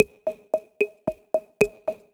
Track 15 - Electro Congo.wav